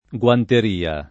guanteria [ gU anter & a ] s. f.